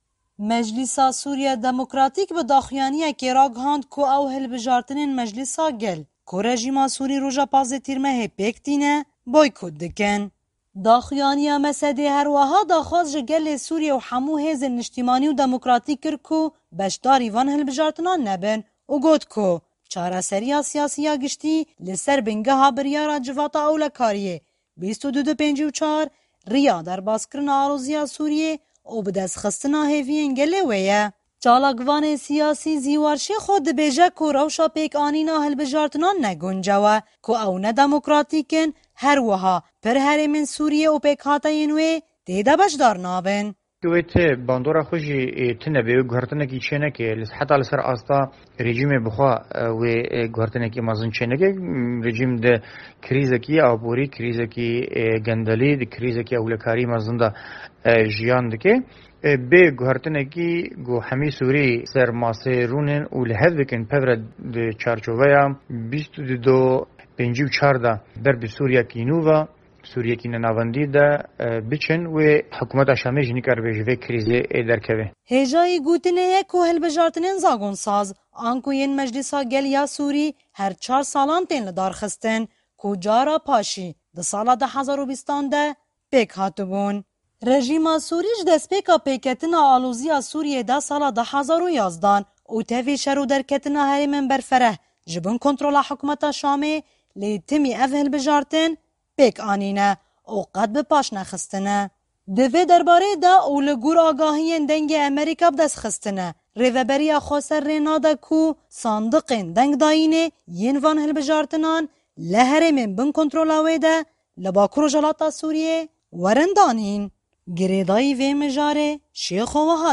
Raporta Deng